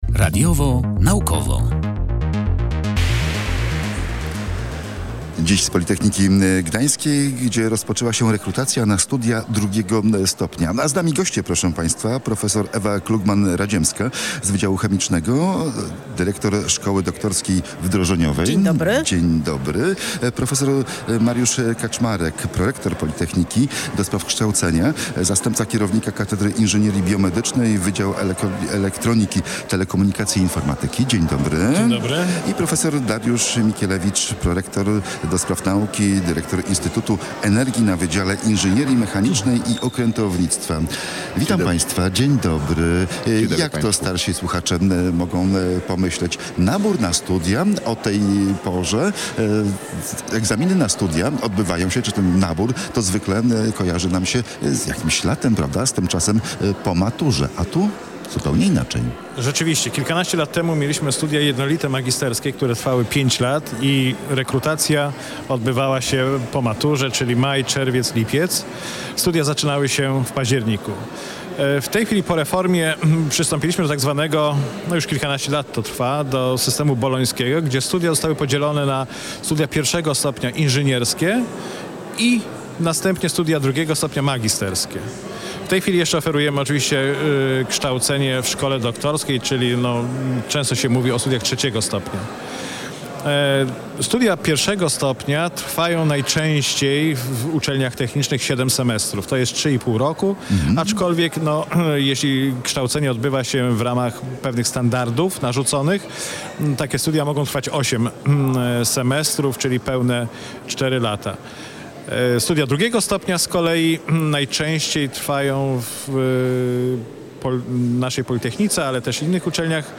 Z okazji dnia otwartego dla kandydatów, na terenie Politechniki pojawiło się nasze mobilne studio.